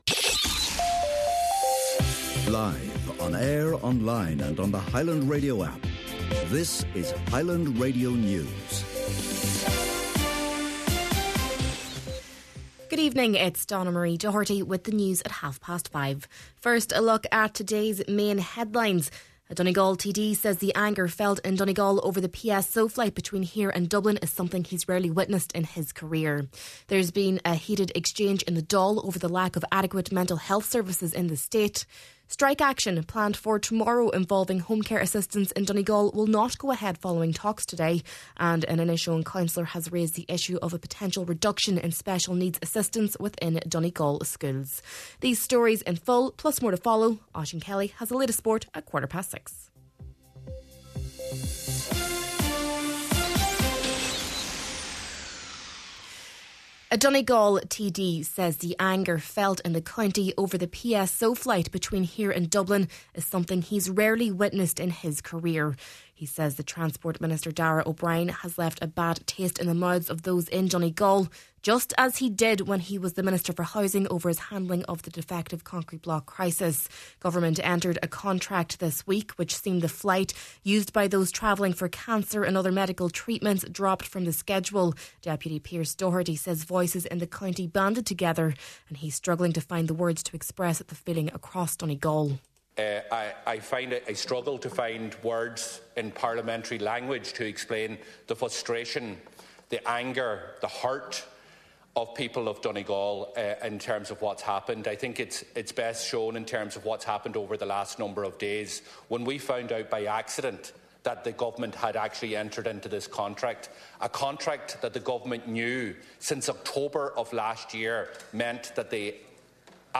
Main Evening News, Sport, Farming News and Obituary Notices – Thursday, February 12th